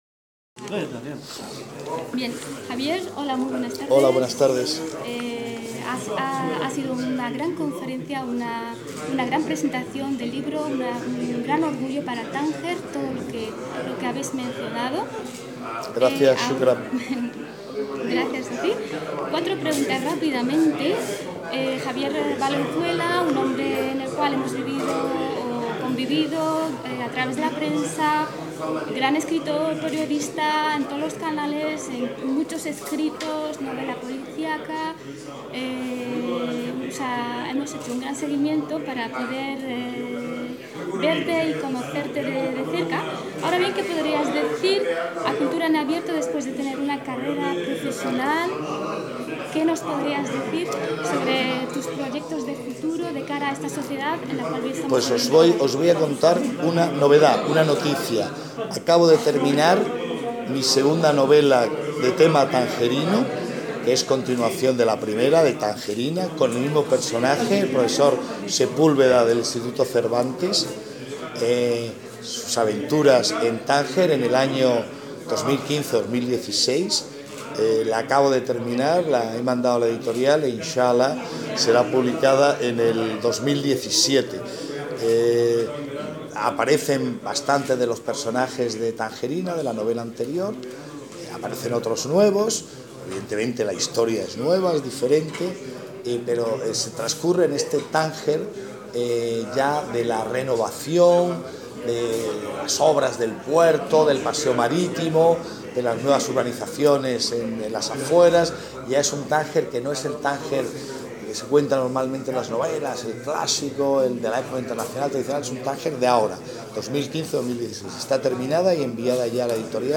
ENTREVISTA A JAVIER VALENZUELA